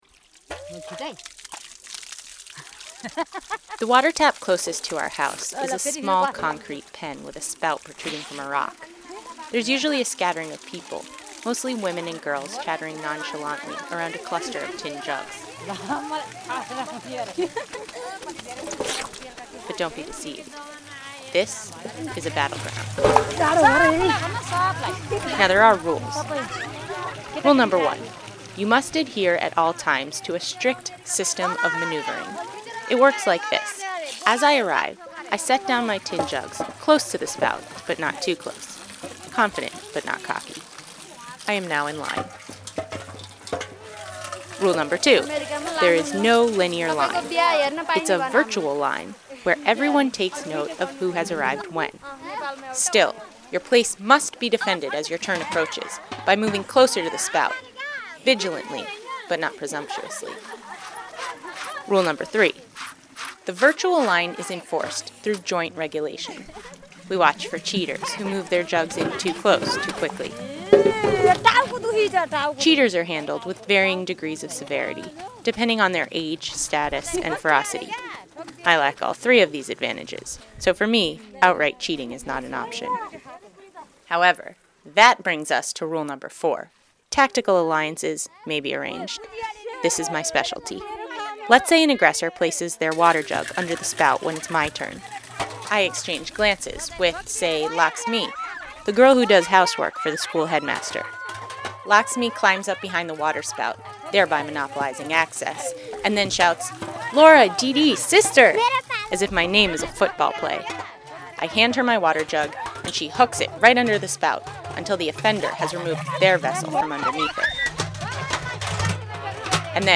water-tap-fnl.mp3